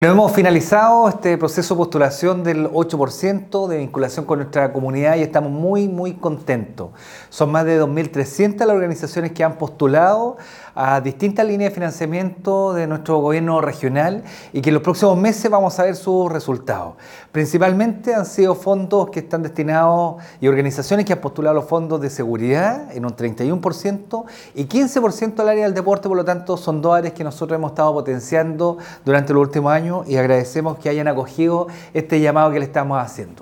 El Gobernador Regional, Óscar Crisóstomo, destacó la alta participación ciudadana señalando que “son más de 2.300 organizaciones que han postulado a distintas líneas de financiamiento; principalmente a Seguridad y Deporte, dos áreas que hemos potenciado durante el último año”.